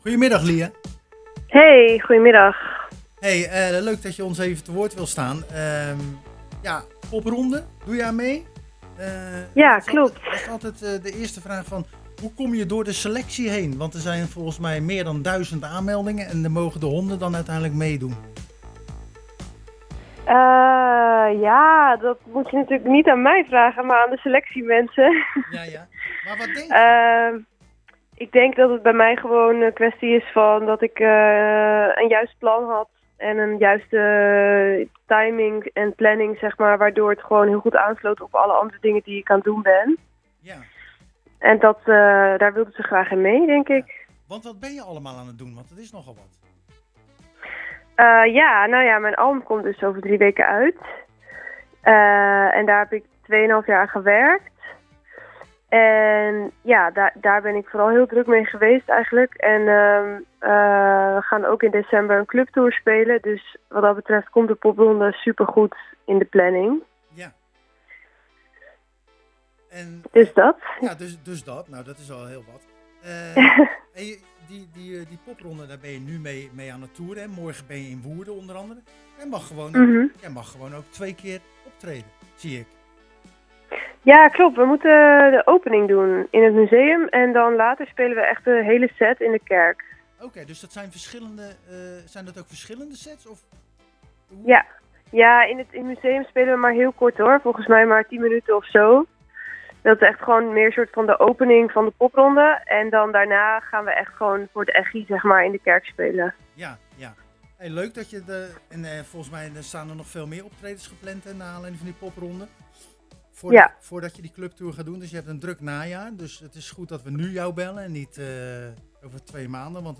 telefonisch te gast tijdens de wekelijkse uitzending